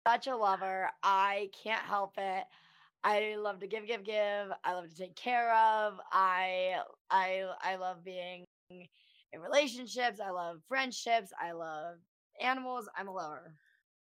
JoJo Siwa Podcast interview.